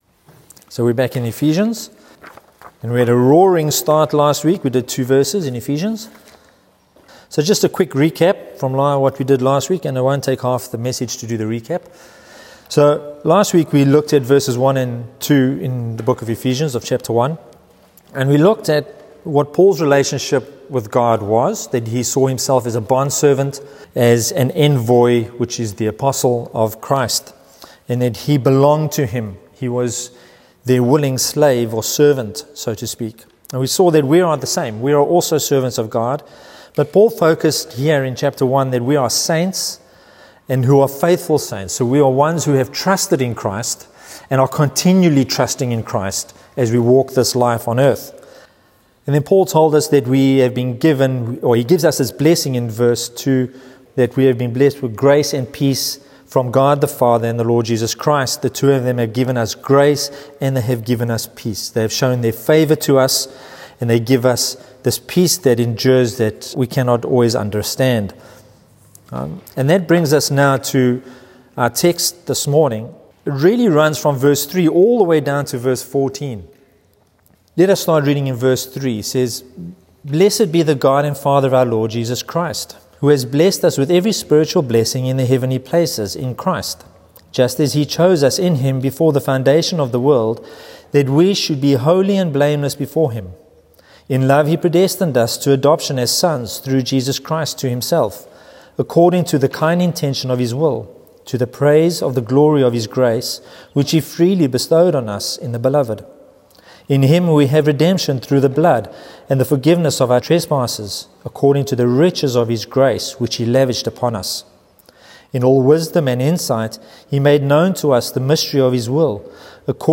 In this sermon we slow down over Paul’s summary at the start of his opening doxology in Ephesians 1:3 and listen as he bursts into praise: “Blessed be the God